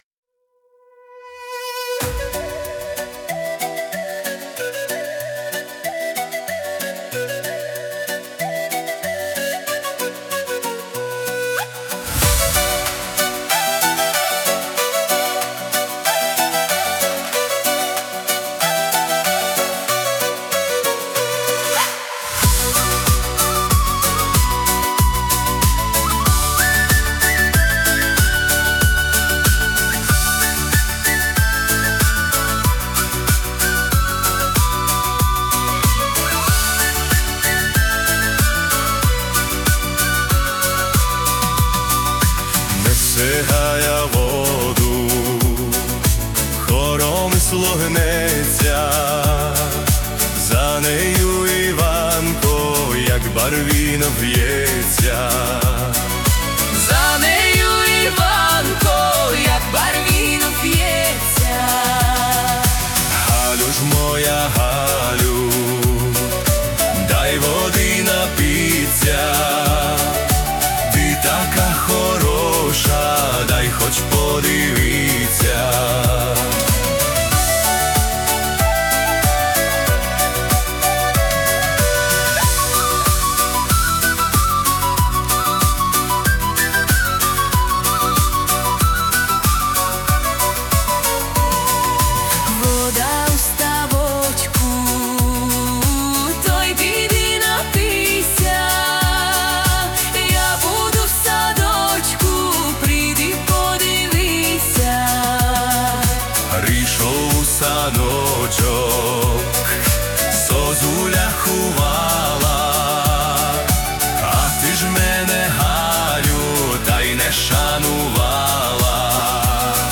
Upbeat, Punchy Drums, High Energy